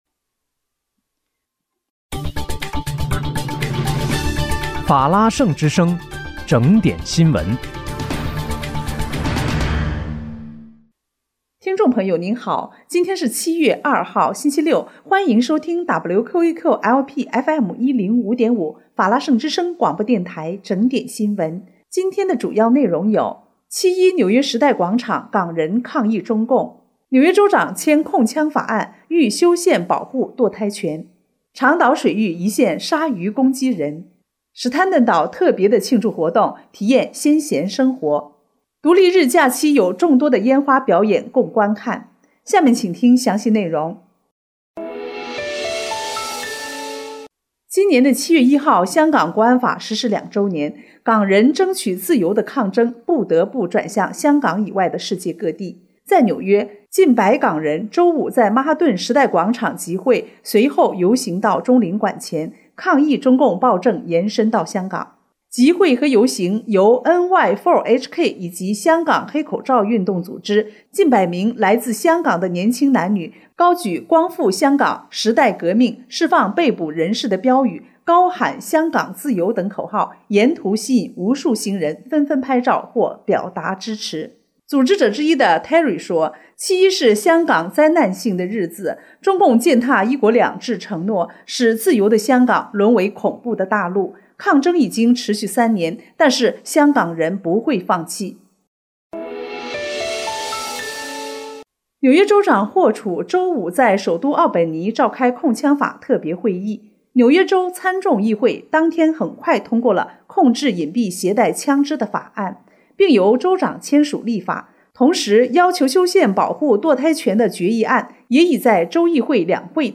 7月2日（星期六）纽约整点新闻
在听众朋友您好！今天是7月2号，星期六，欢迎收听WQEQ-LP FM105.5法拉盛之声广播电台整点新闻。